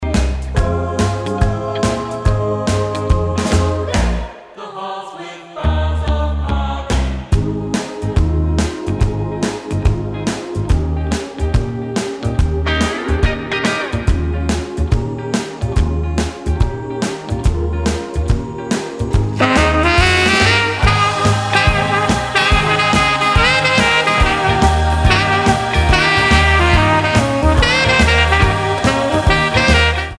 Tonart:Ab mit Chor
Die besten Playbacks Instrumentals und Karaoke Versionen .